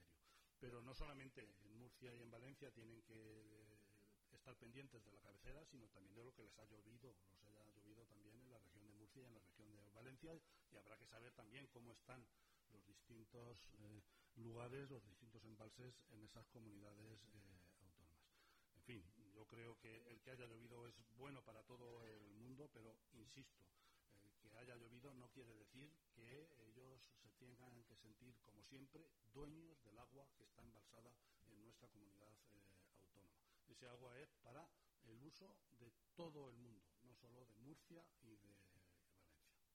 En declaraciones a los medios de comunicación, Martínez Guijarro se ha alegrado del aumento del caudal en los pantanos de la cabecera del Tajo, los de Entrepeñas y Buendía, que alcanzan los 1.000 hectómetros cúbicos por primera vez desde el año 2012, pero ha advertido que esa agua es “para el uso de todo el mundo, no solo de Murcia y de Valencia”, después de que el presidente murciano haya aludido a las abundantes lluvias y el aumento del agua embalsada para exigir el mantenimiento del trasvase.